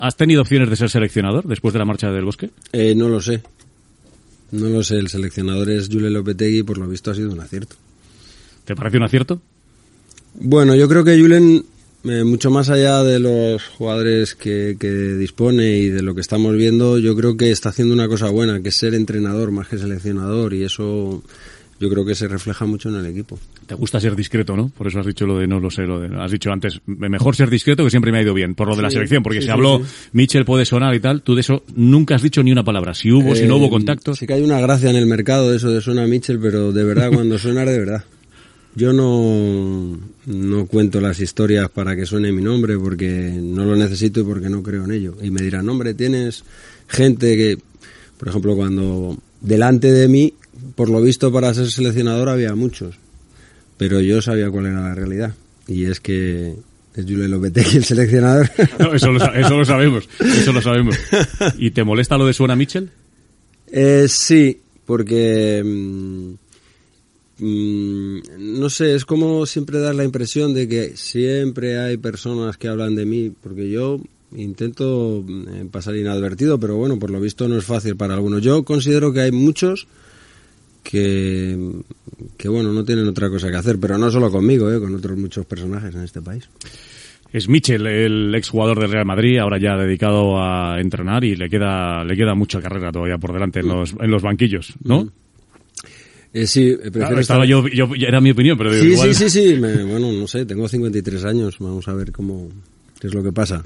Fragment d'una entrevista a l'ex futbolista i entrenador Michel ( José Miguel González Martín ).
Esportiu